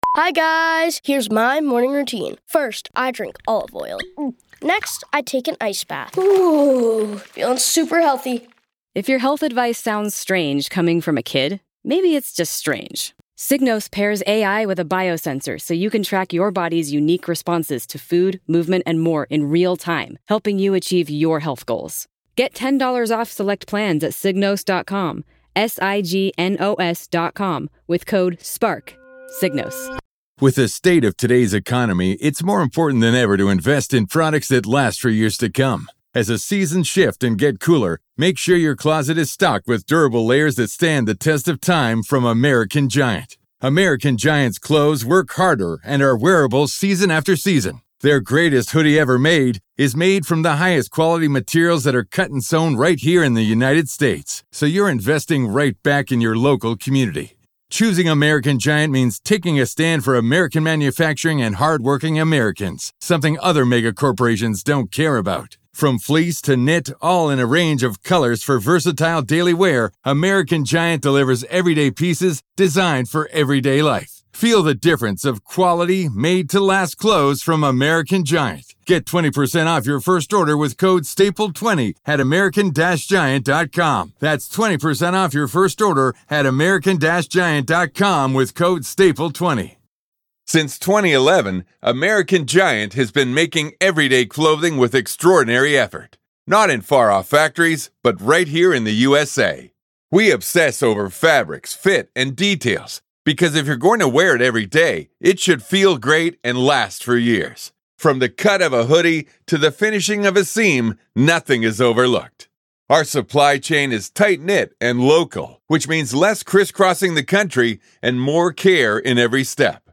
This footage was shot just minutes after the suspect fled the scene , and it may be the biggest breakthrough yet in the manhunt. In our conversation, the witness gives a detailed breakdown of what he saw and heard: A black four-door sedan , possibly German or foreign-made , with a sleek design, quad exhaust tips , tinted windows , and matte or semi-gloss paint .